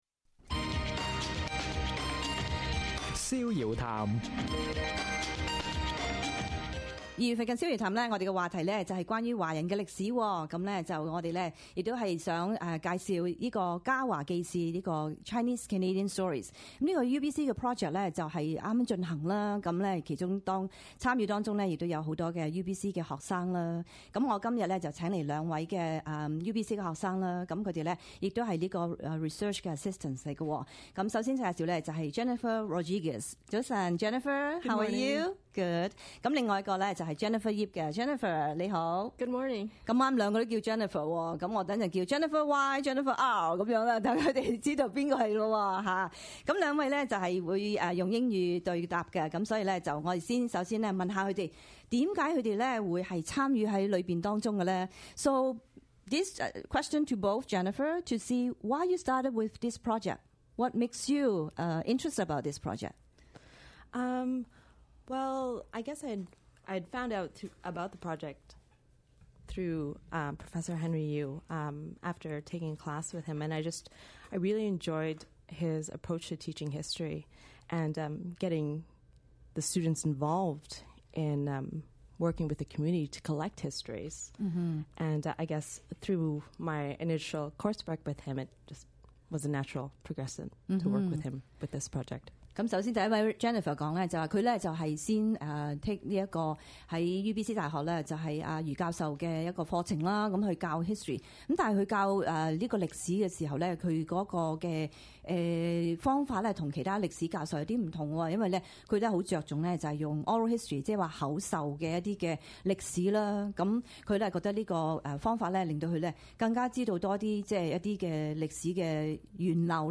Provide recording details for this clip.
For the month of February in 2011, on Saturday mornings, the Chinese Canadian Stories project recordings were broadcast to the Lower Mainland’s large Cantonese audience.